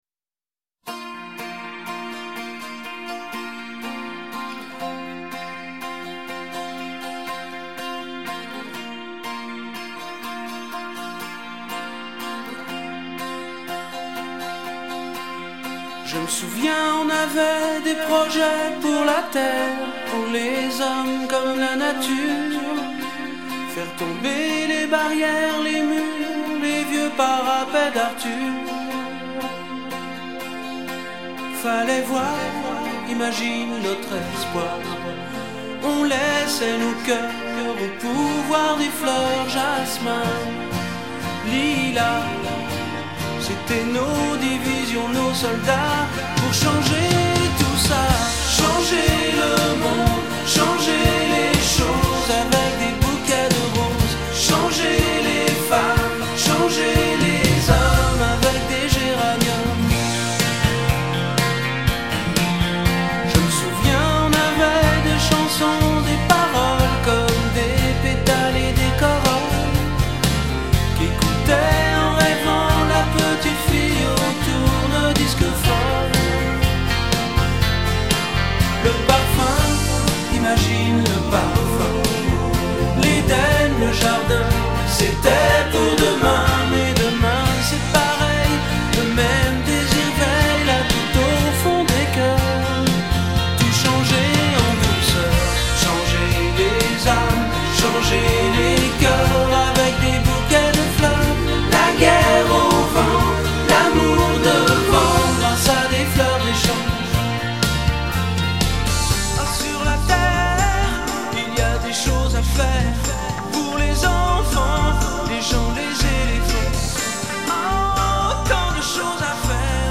tonalité LA majeur